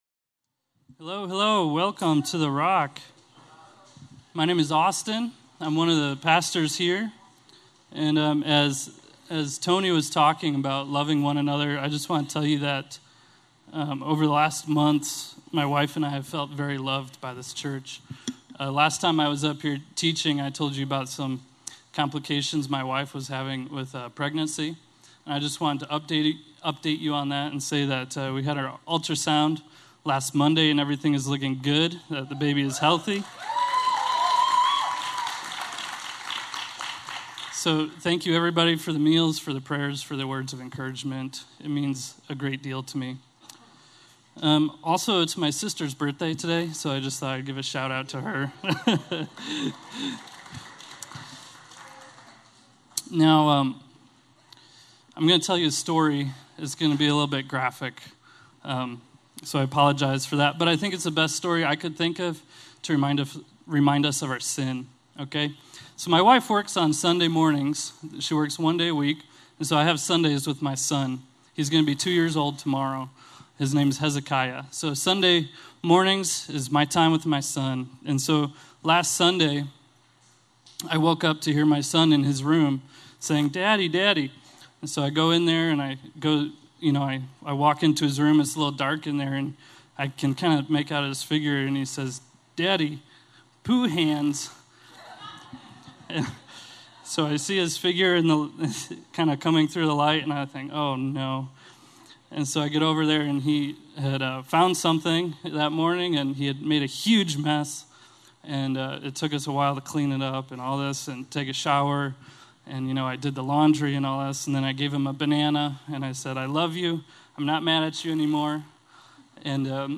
A message from the series "Your Love Remains."